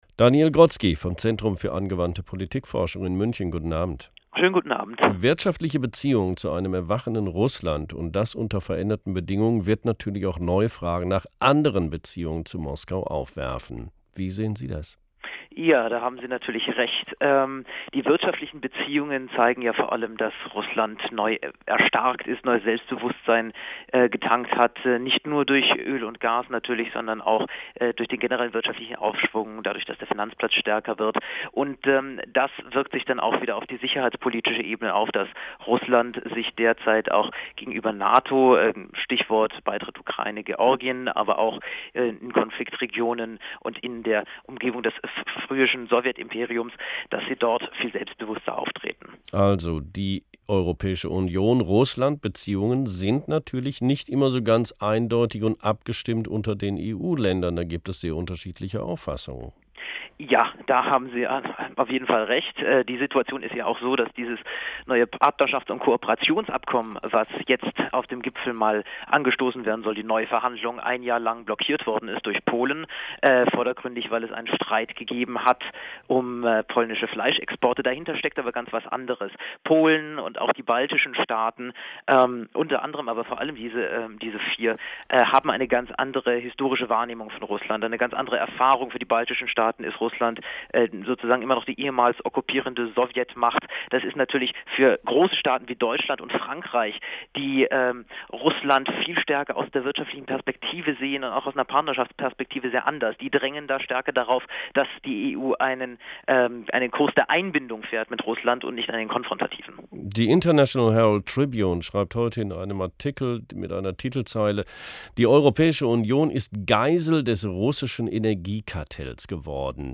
Audio-Interview